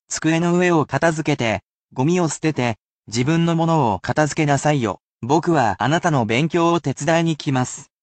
However, it will be at normal speed which may be difficult for beginners, but should assist you in getting used to the speed of the language, but this will act as useful listening practise.
[basic polite speech]